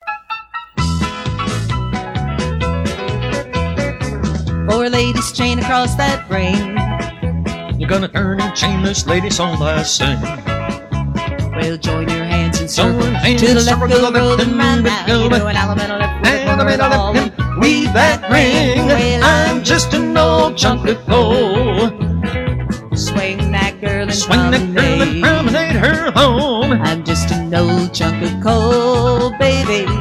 Singing Call
Voc